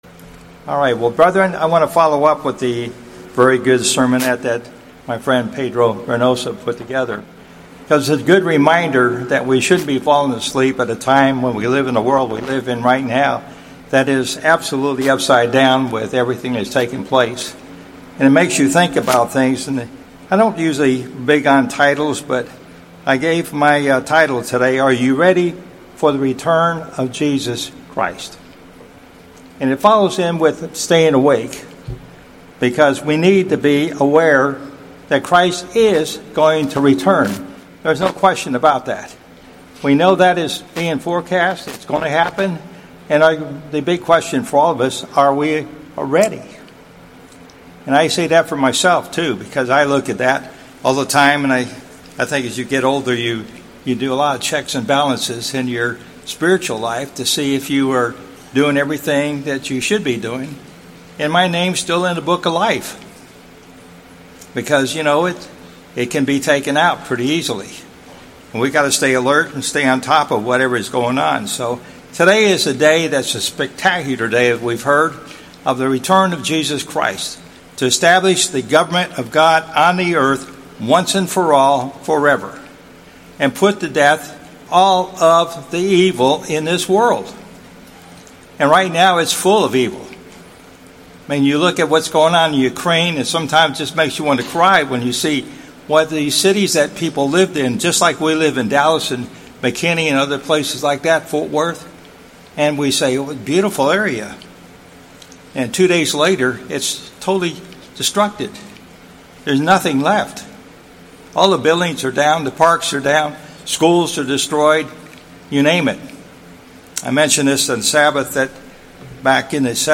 Sermons
Given in Dallas, TX Fort Worth, TX